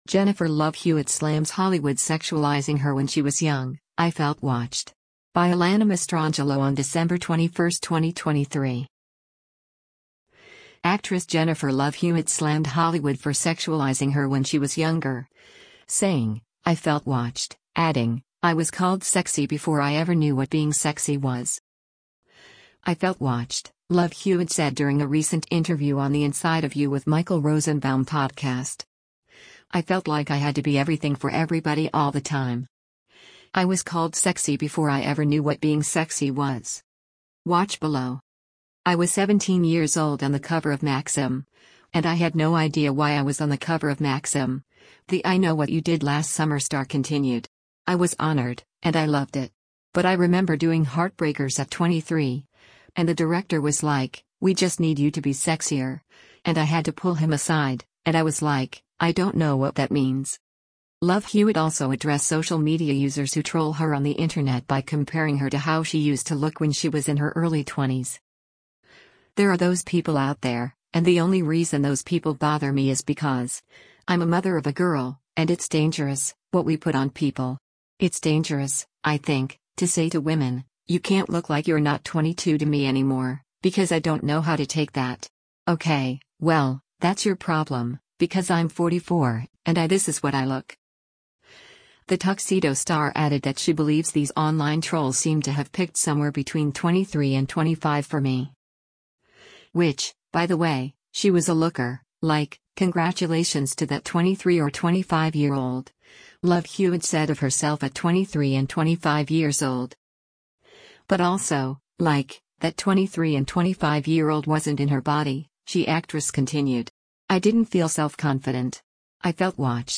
“I felt watched,” Love Hewitt said during a recent interview on the Inside of You With Michael Rosenbaum podcast.